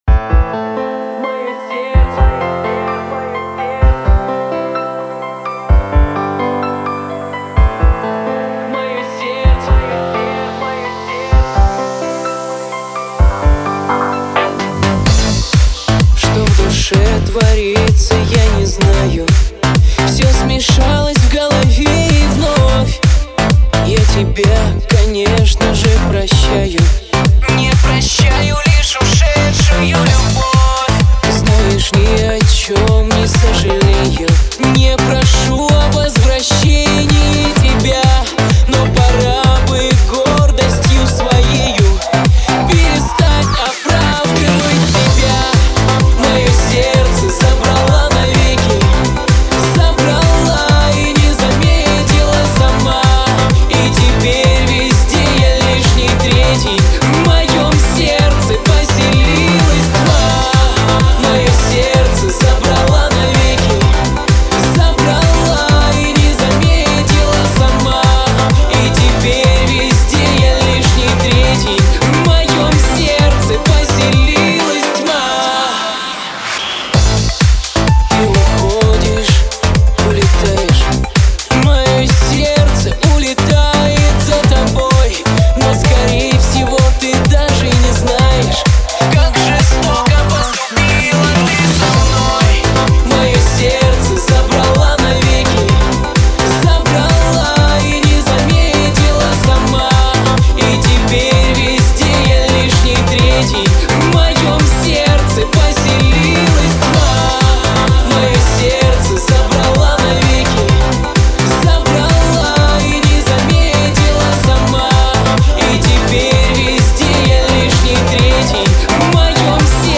Жанр: Dance music
лучшая поп музыка